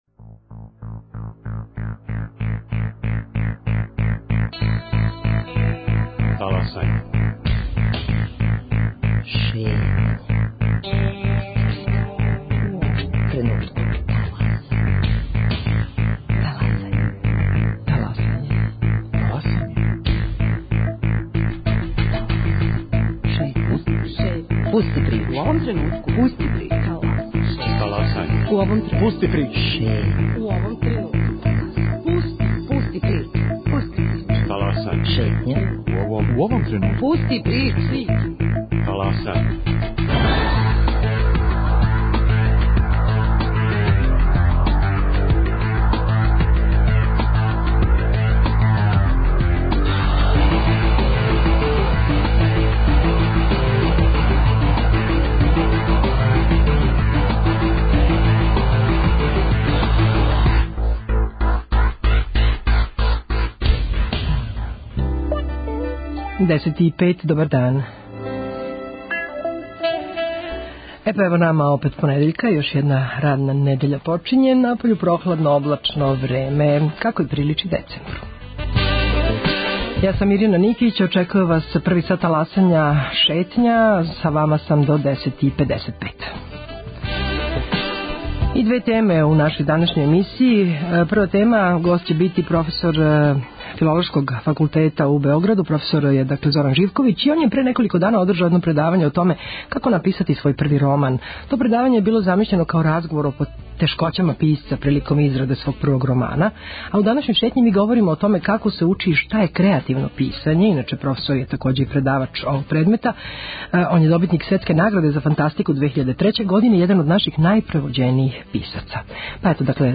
У Шетњи говоримо о томе како се учи и шта је креативно писање, а наш гост је Зоран Живковић, добитник Светске награде за фантастику 2003. године, један од наших најпревођенијих писаца, и редовни професор на Филолошком факултету Универзитета у Београду.